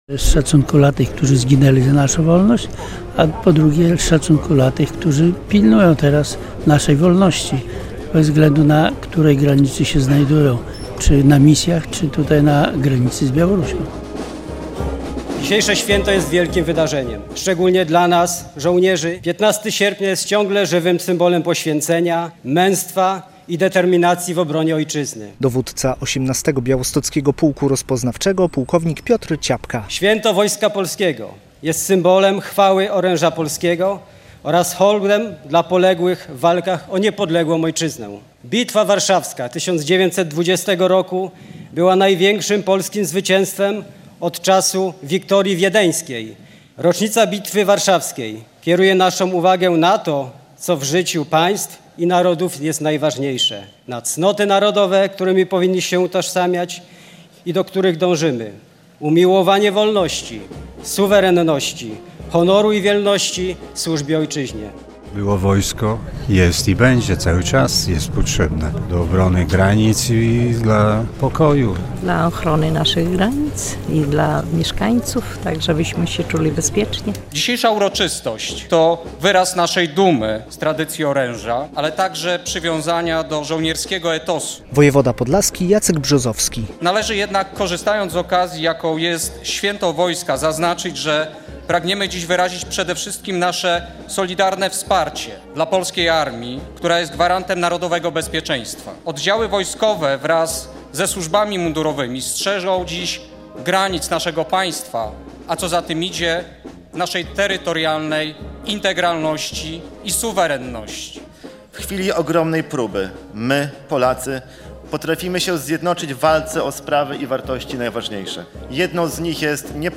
Święto Wojska Polskiego w Białymstoku - relacja